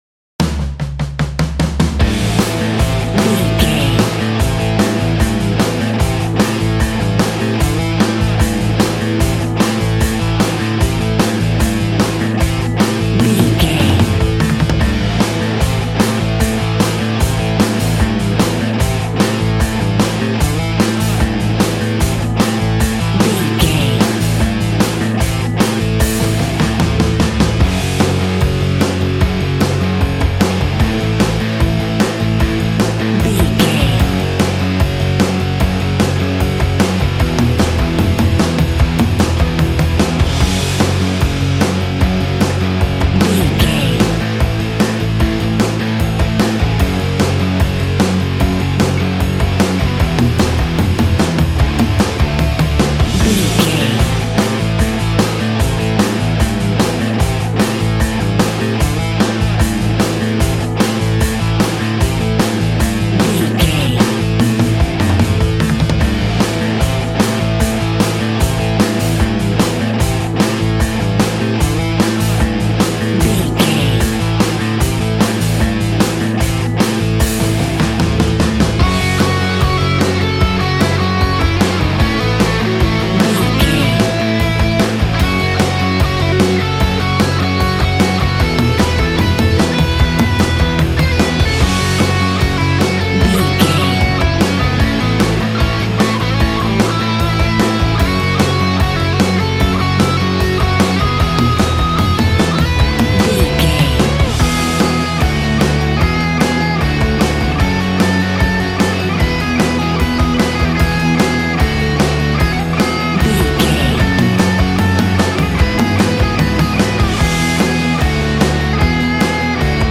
Ionian/Major
angry
aggressive
electric guitar
drums
bass guitar